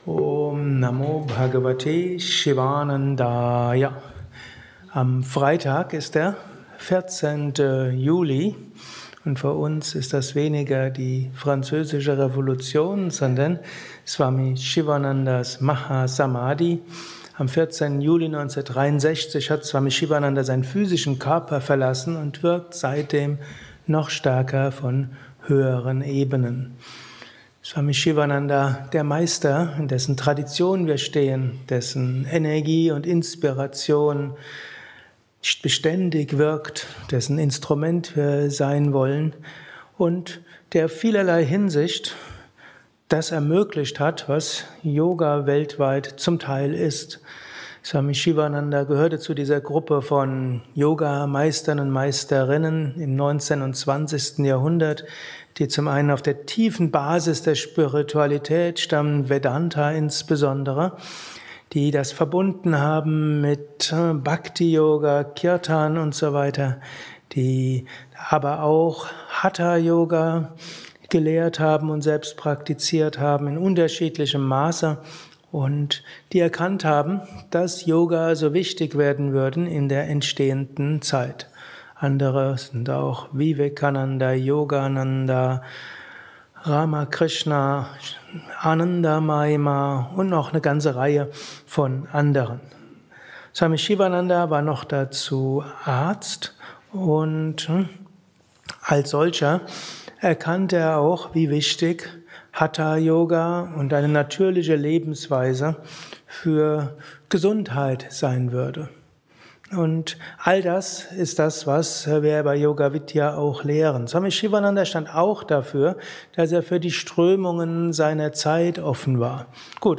Kurzvorträge
Satsangs gehalten nach einer Meditation im Yoga Vidya Ashram Bad